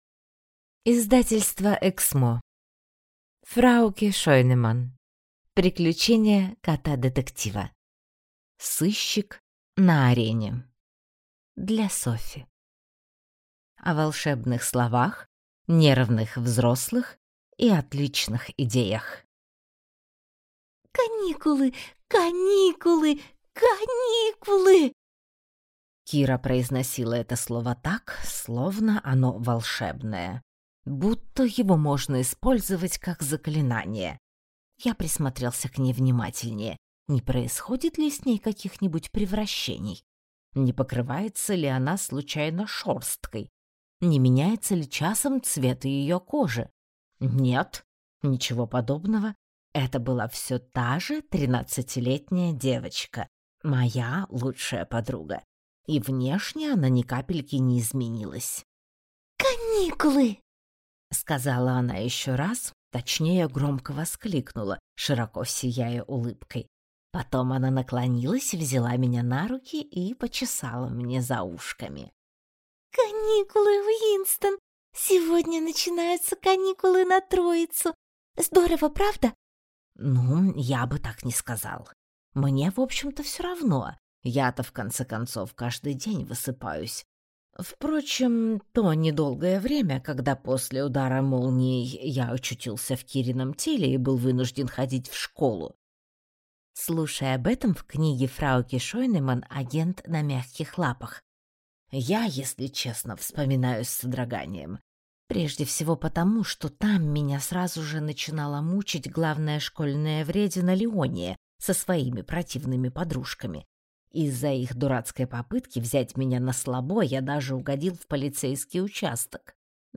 Аудиокнига Сыщик на арене | Библиотека аудиокниг